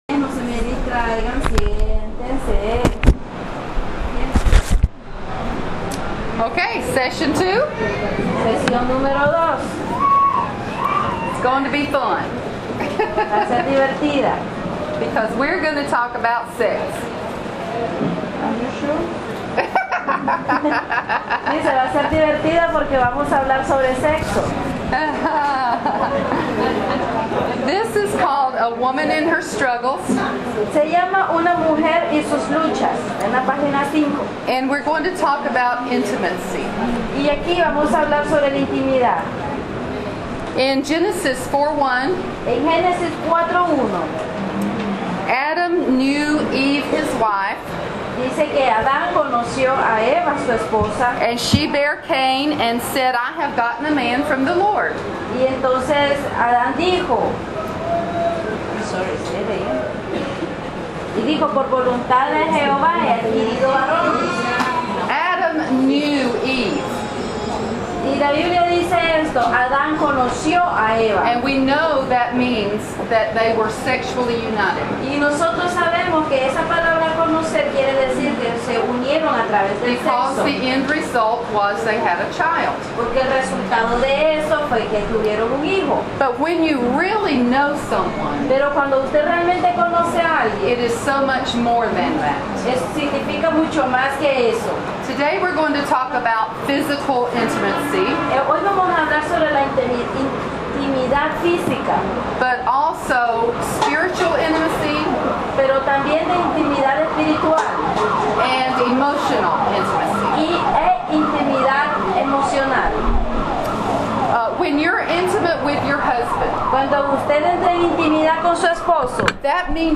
A Woman and Her Struggles (translated into Spanish) presented at Pastors' wives conference at Luz y Vida church in Corinto, Nicaragua, July 27, 2012